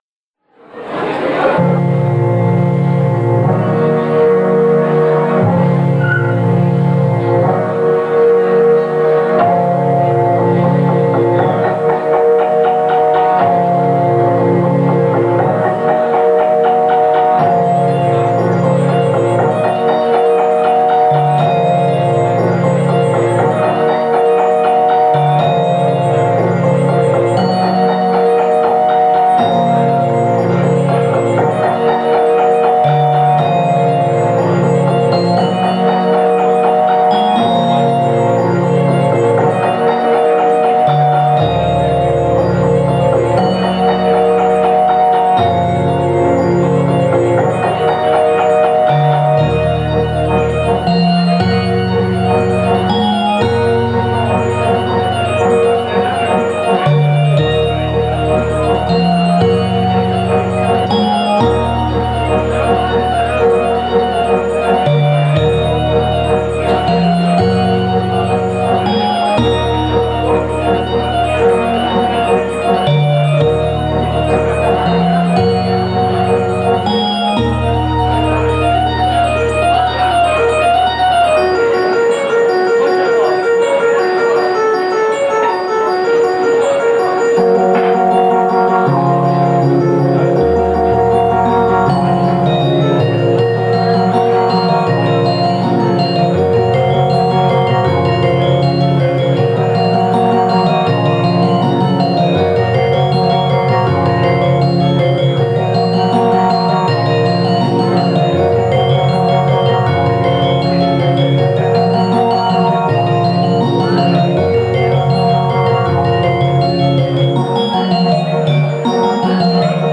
гитара, вокальные сэмплы
клавишные, вокал, плейбек.